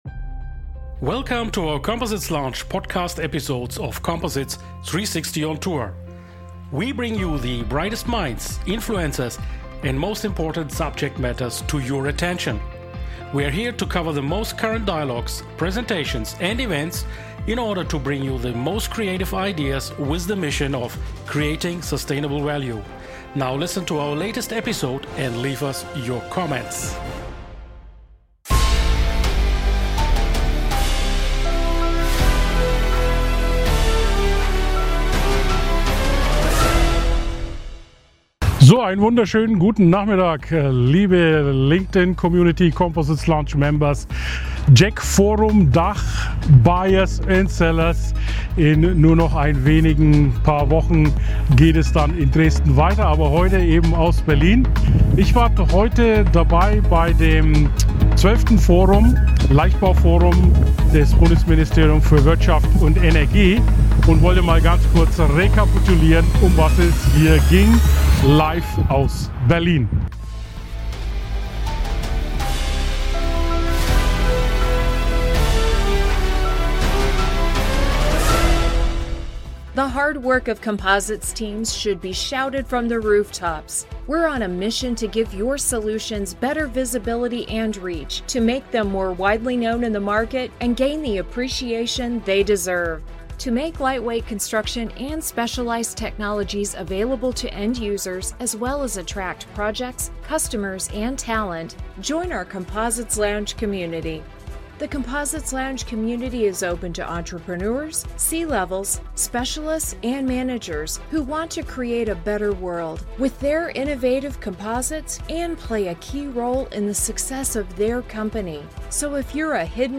Forum Leichtbau des Bundesministerium für Wirtschaft und Energie in Berlin Am 25. September 2025 war die Composites Lounge live dabei, als das BMWE zum 12. Forum Leichtbau in die Bundesanstalt für Materialforschung und -prüfung (BAM) Unter den Eichen in Berlin einlud.